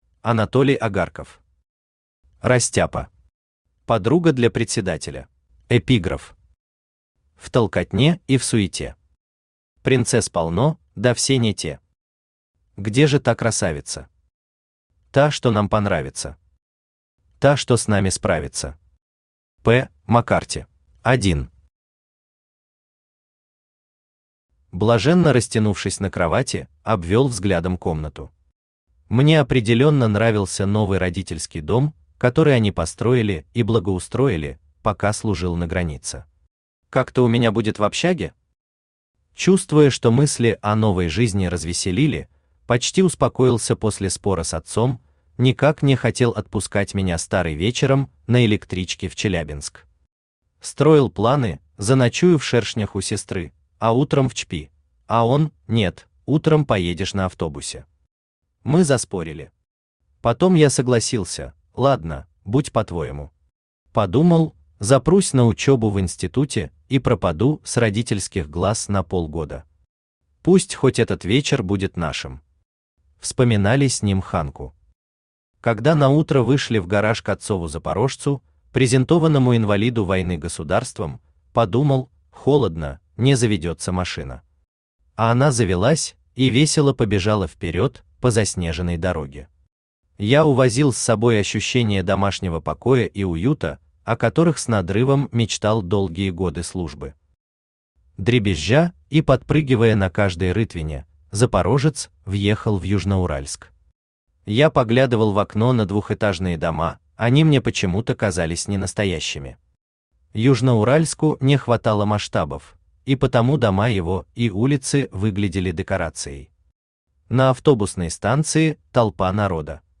Аудиокнига Растяпа. Подруга для председателя | Библиотека аудиокниг
Подруга для председателя Автор Анатолий Агарков Читает аудиокнигу Авточтец ЛитРес.